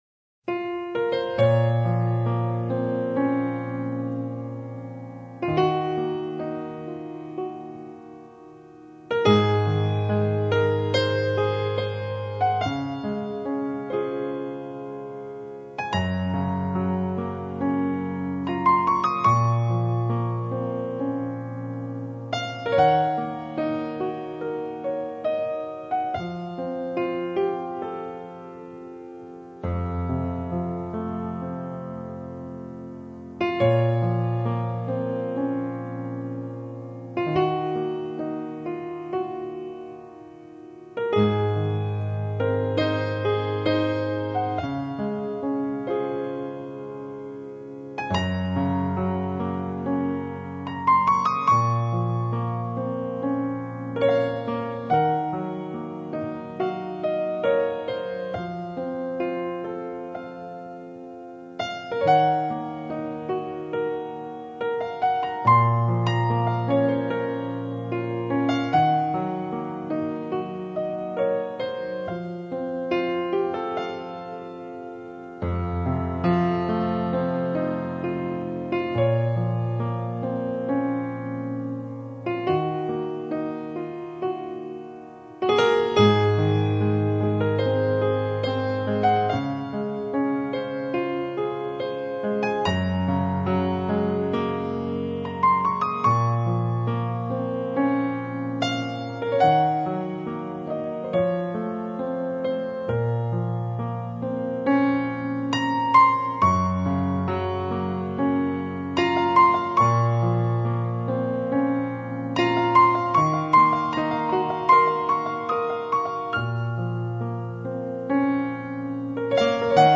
曲风缓慢恬静，带着丝丝淡淡的忧伤